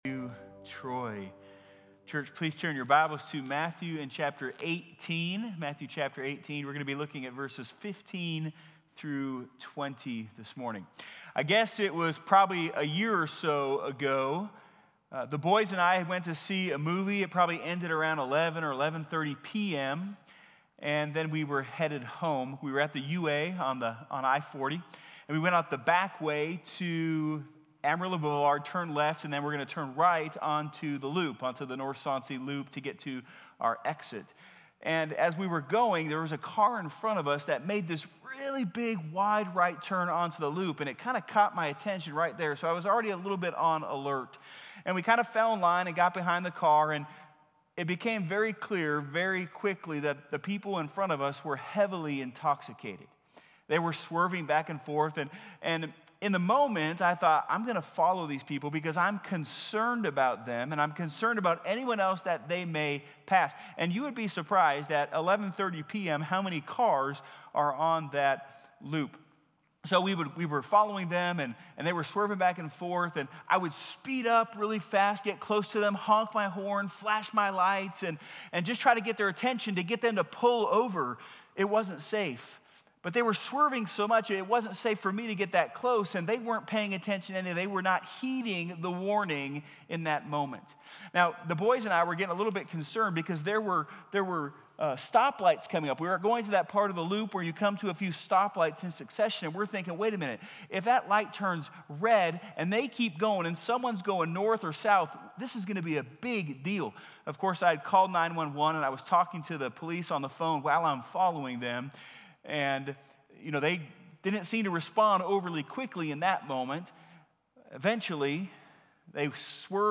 Trinity Baptist Church - Sermons - Trinity Baptist